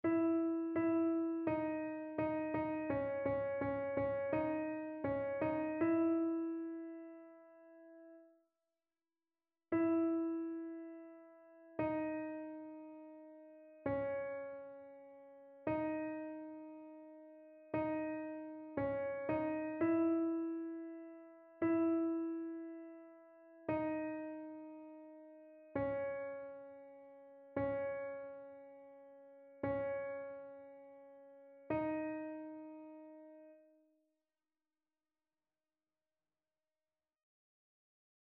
AltoTénorBasse